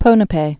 (pōnə-pā)